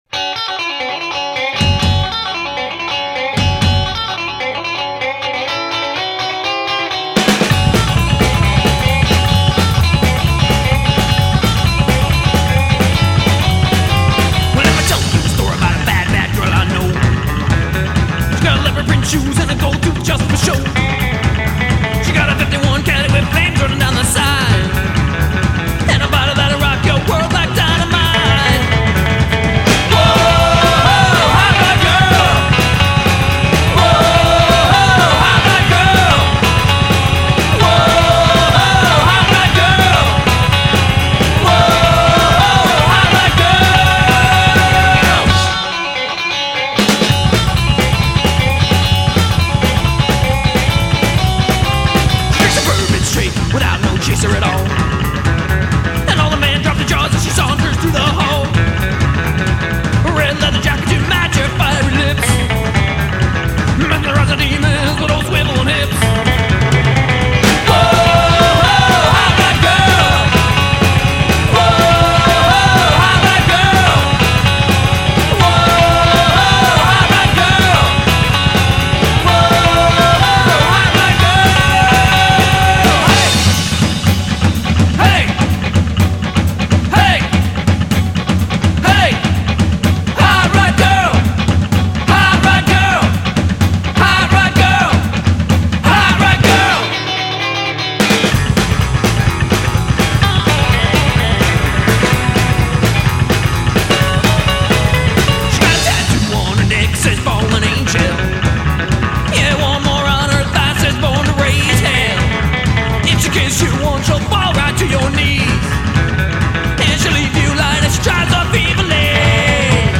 guitar and vocals
upright bass and vocals
drums